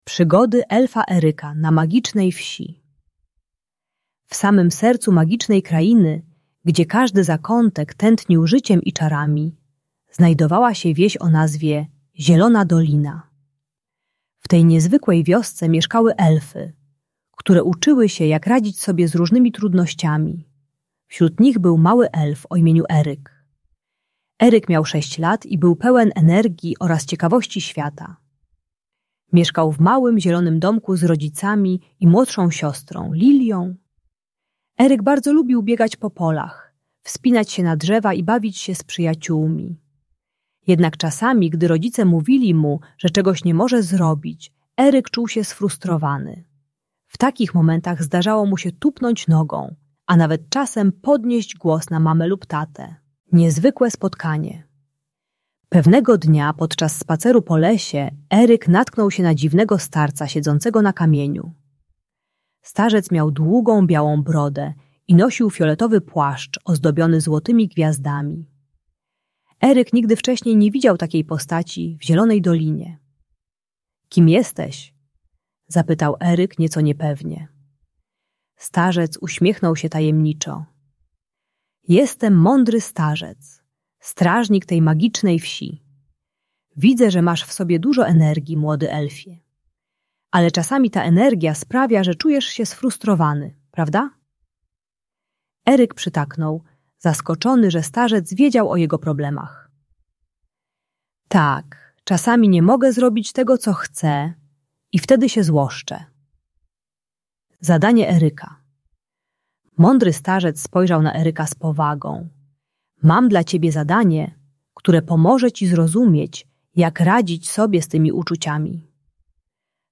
Przygody Elfa Eryka - Agresja do rodziców | Audiobajka